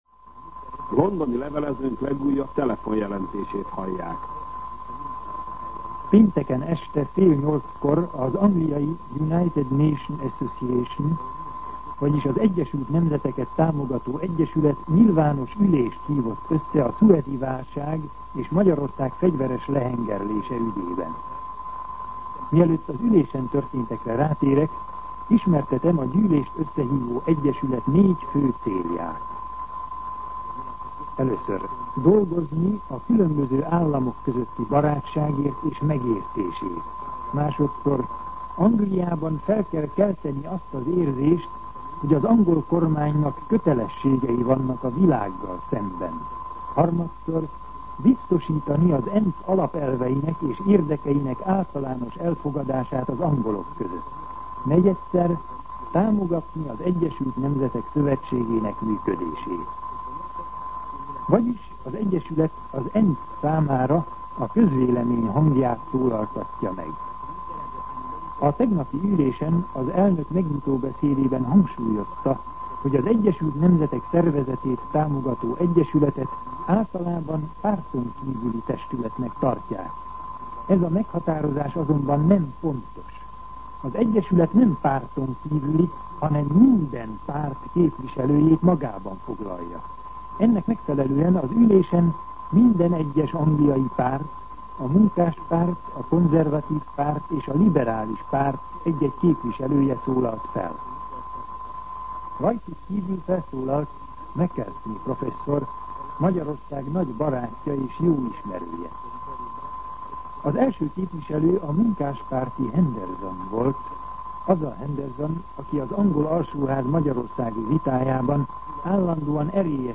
Londoni levelezőnk legújabb telefonjelentését hallják. Pénteken este fél nyolckor az angliai United Nations Association, vagyis az Egyesült Nemzeteket támogató egyesület nyilvános ülést hívott össze a szuezi válság és Magyarország fegyveres lehengerlése ügyében.